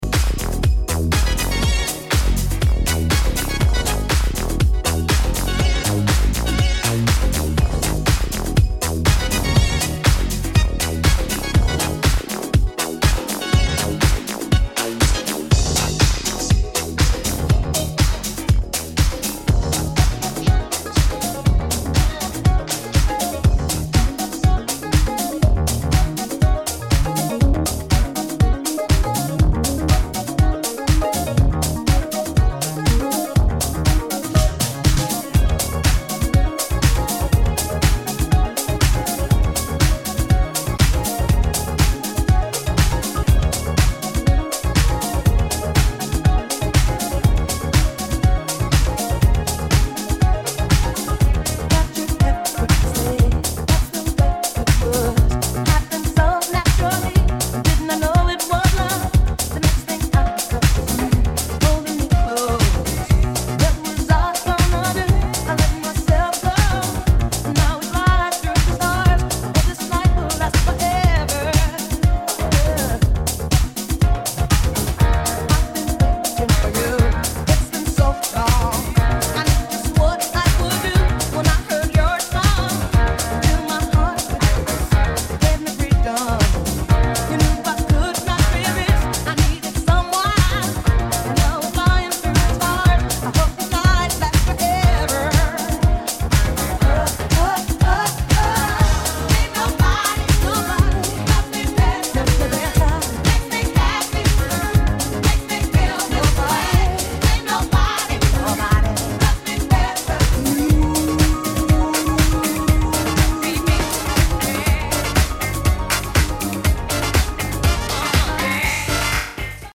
DJ | MC
Open format, can play any genres and style.